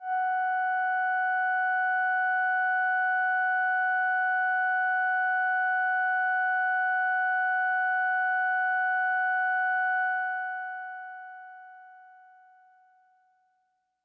标签： MIDI-速度-32 F6 MIDI音符-90 罗兰木星-4 合成器 单票据 多重采样
声道立体声